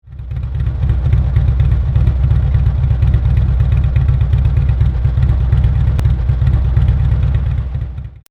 Tuned to give baggers a deep and aggressive roar, the large 4" diameter mufflers really accomplish this task in style.